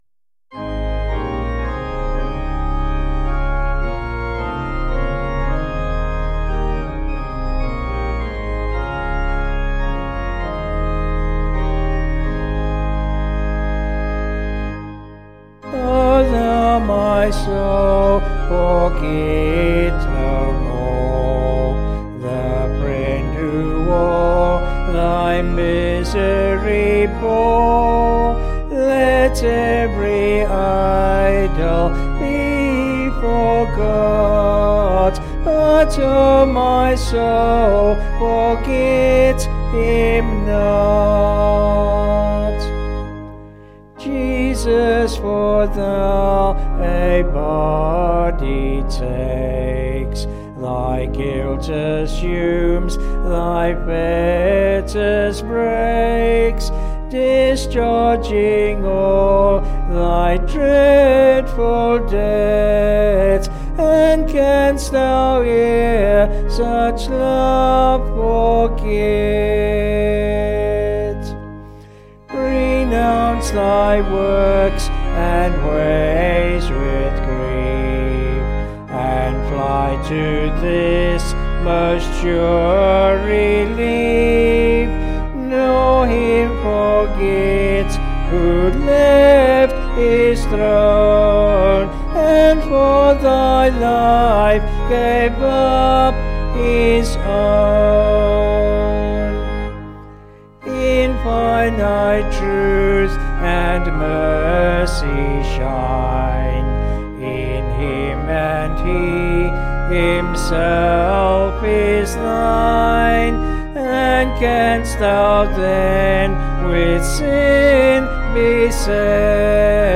Vocals and Organ   265kb